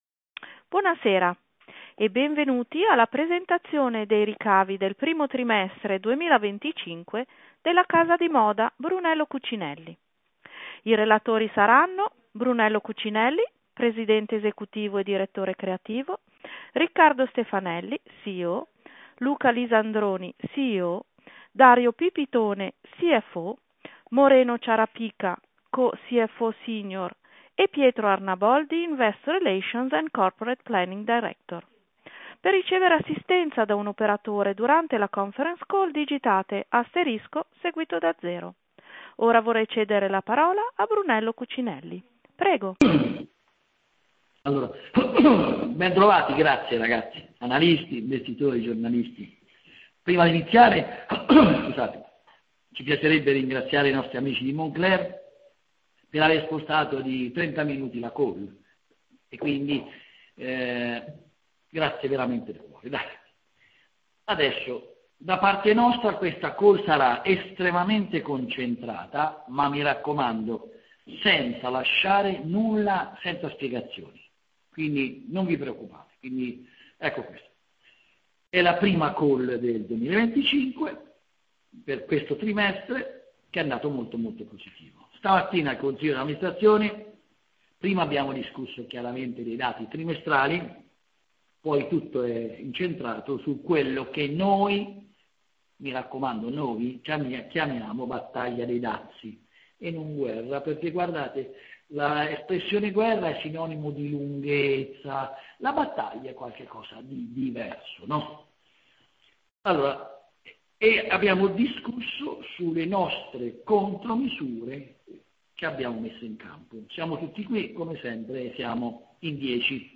18 Venerdì Conference call risultati 9M 2024